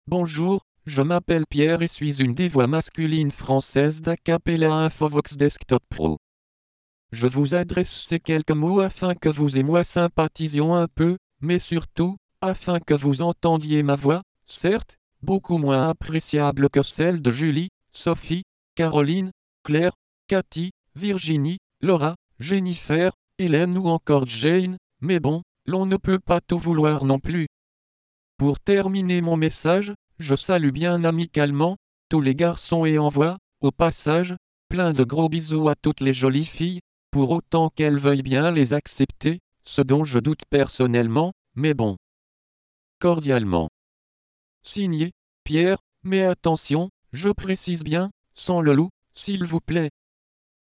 Texte de démonstration lu par Pierre, voix masculine française d'Acapela Infovox Desktop Pro
Écouter la démonstration de Pierre, voix masculine française d'Acapela Infovox Desktop Pro